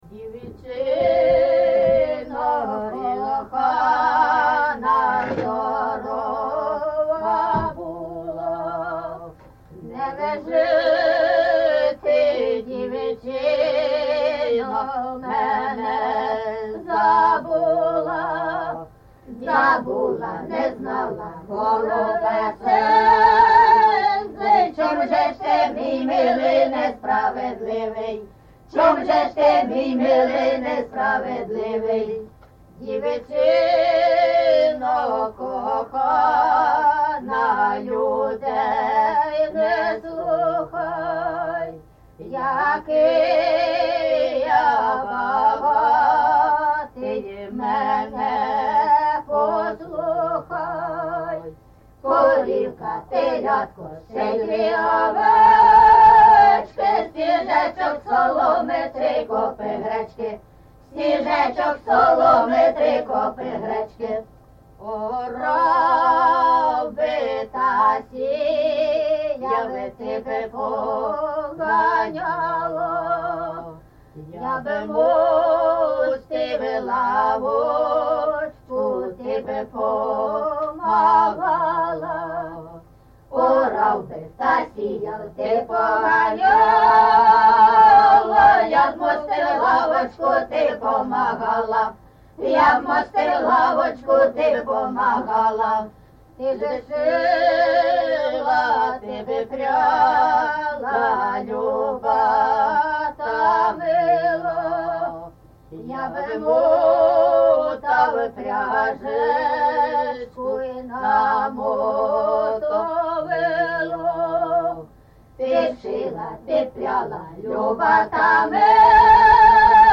ЖанрПісні літературного походження
Місце записус. Лука, Лохвицький (Миргородський) район, Полтавська обл., Україна, Полтавщина